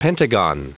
Prononciation du mot pentagon en anglais (fichier audio)
Prononciation du mot : pentagon